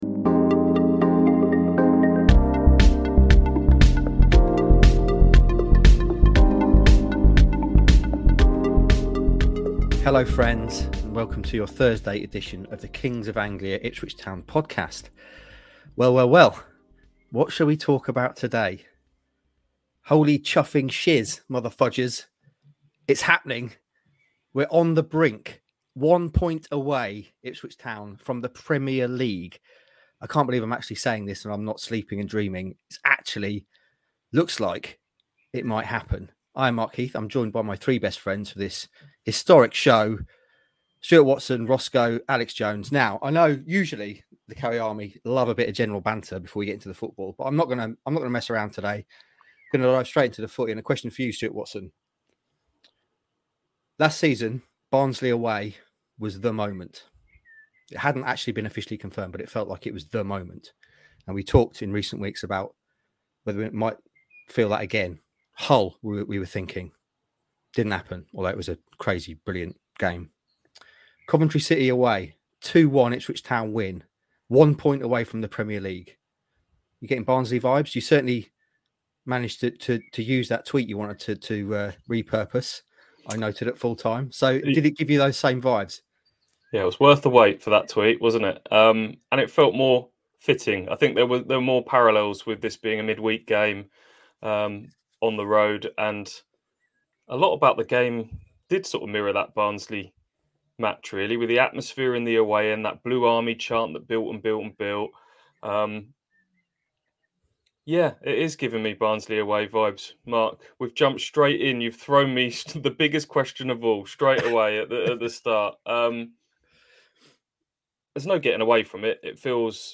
The boys chat about the display, the experience and the atmosphere on a night which will live long in the memory. We also hear from Kieran McKenna and Sam Morsy